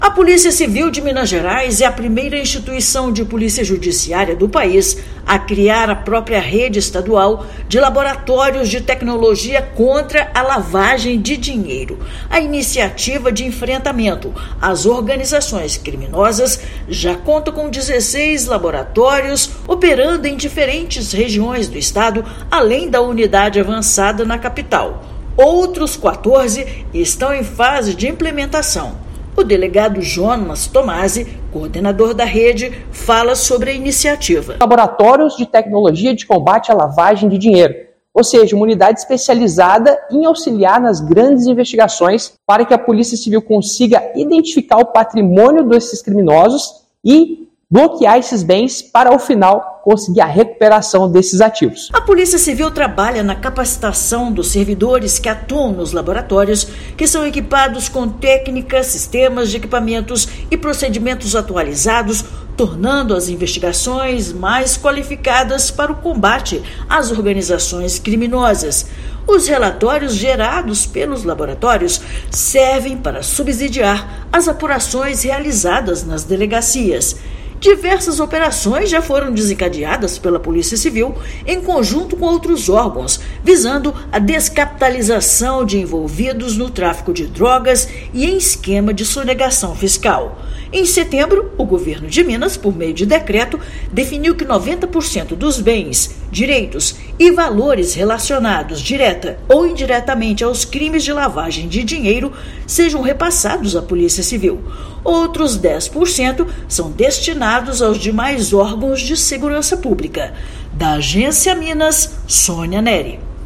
[RÁDIO] Minas é pioneira na criação da Rede de Laboratórios de Combate à Lavagem de Dinheiro
Polícia Civil já conta com 17 LAB-LD, de um total de 31 unidades previstas para reforçar o combate ao crime organizado no estado. Ouça matéria de rádio.[